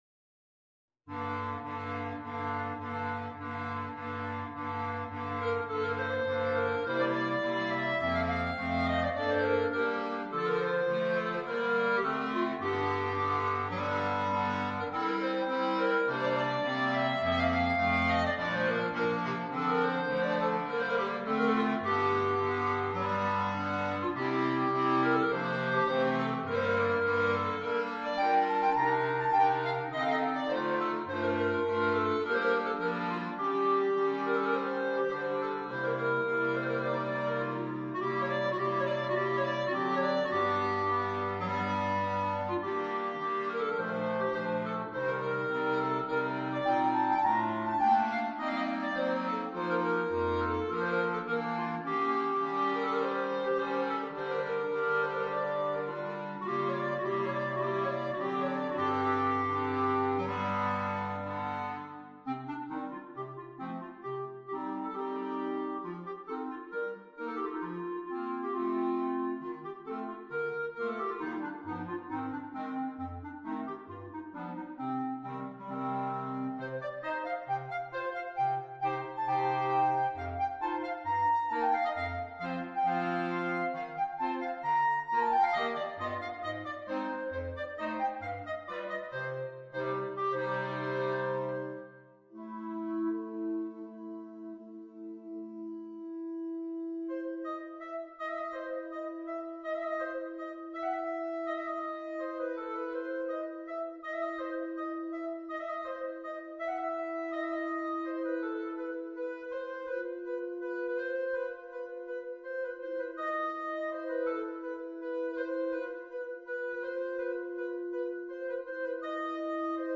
QUINTETTO
Clarinetto alto , Clarinetto basso